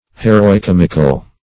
Definition of heroicomical.
Search Result for " heroicomical" : The Collaborative International Dictionary of English v.0.48: Heroicomic \He`ro*i*com"ic\, Heroicomical \He`ro*i*com"ic*al\, a. [Cf. F. h['e]ro["i]comigue.